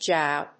/ʒaʊ(米国英語)/